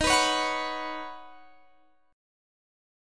Listen to D#m7#5 strummed